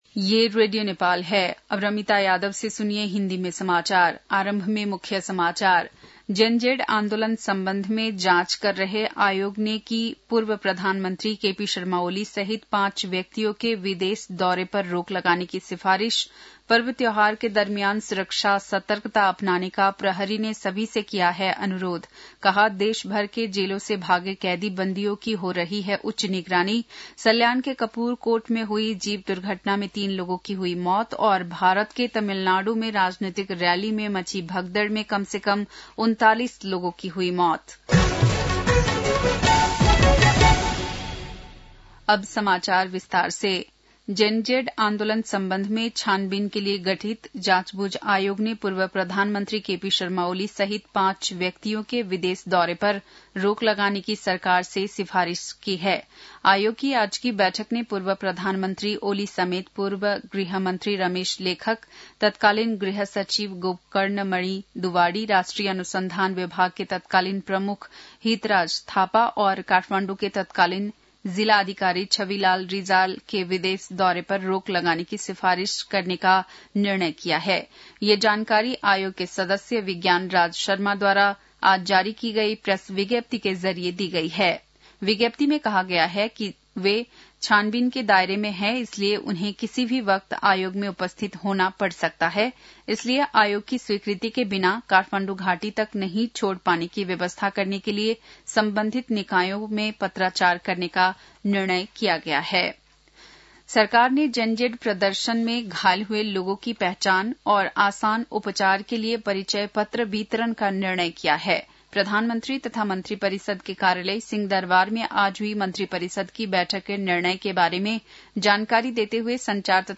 बेलुकी १० बजेको हिन्दी समाचार : १२ असोज , २०८२
10-pm-hindi-news-6-12.mp3